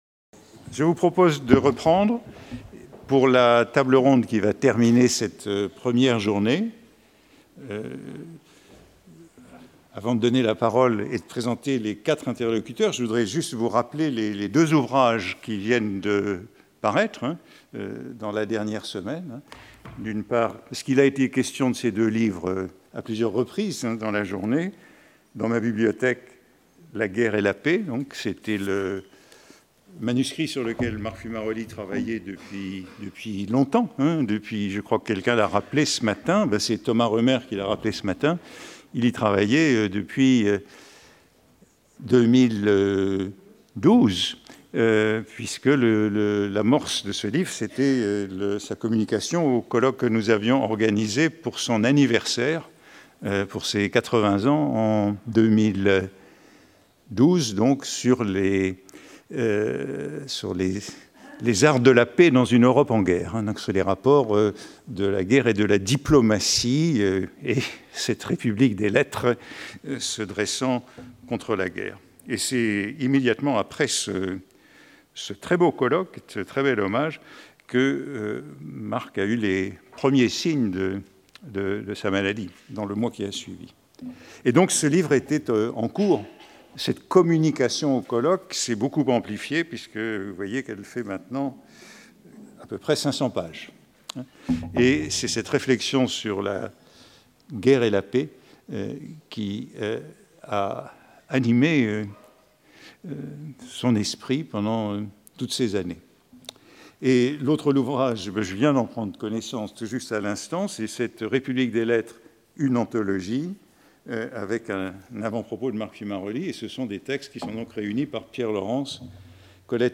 Table ronde | Collège de France